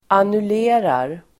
Uttal: [anul'e:rar]
annullerar.mp3